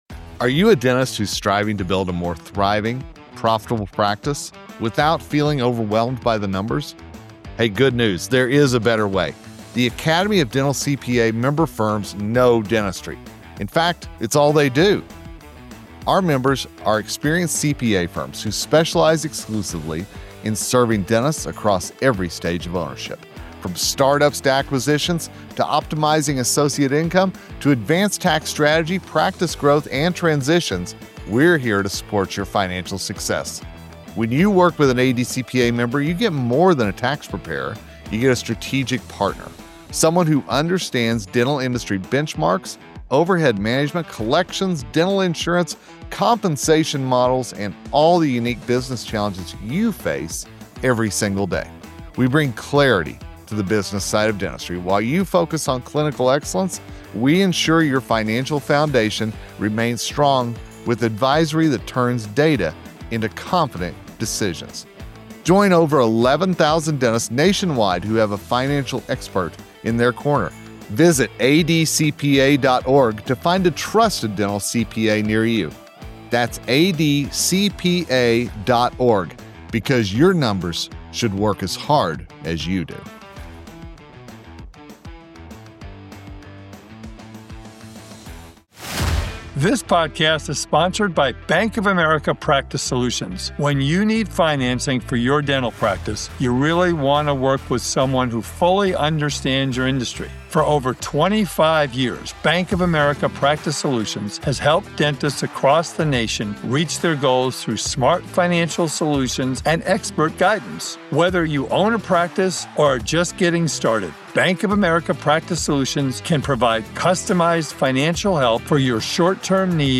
The conversation covers how dentists can improve profitability without working harder by focusing on budgeting, overhead control, fee strategy, and smarter use of data and metrics.